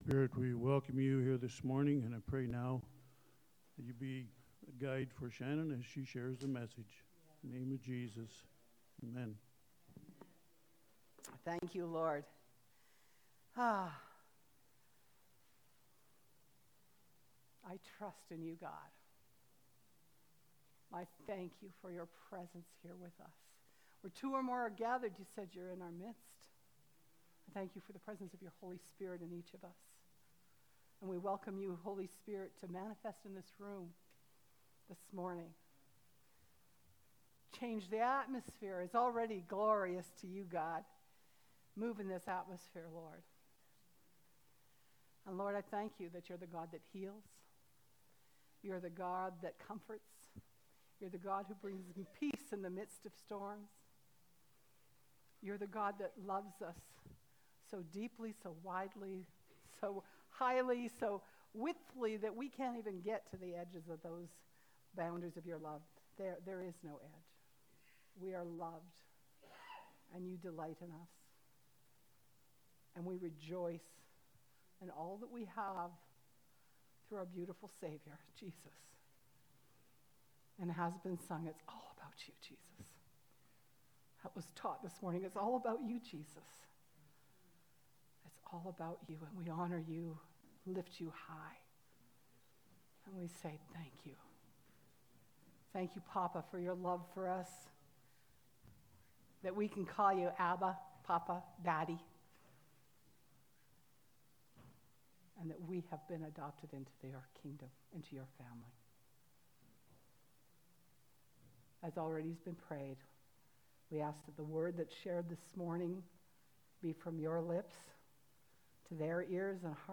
March-30-Sermon-Only.mp3